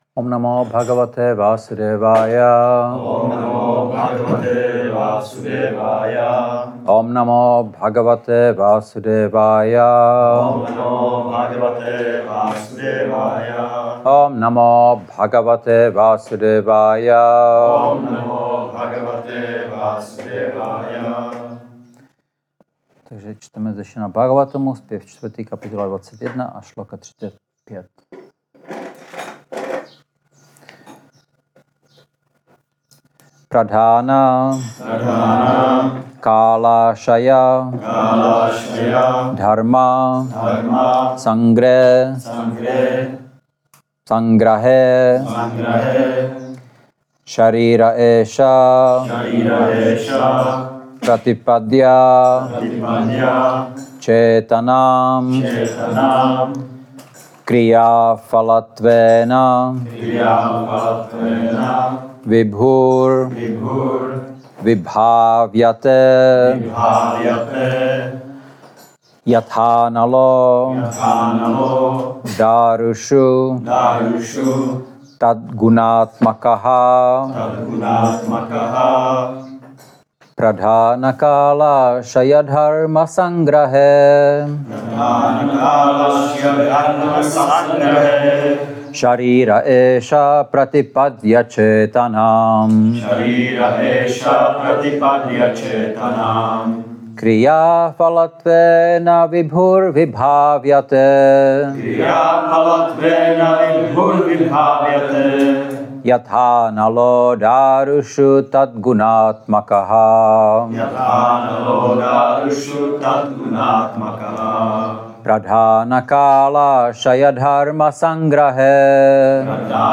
Šrí Šrí Nitái Navadvípačandra mandir
Přednáška SB-4.21.35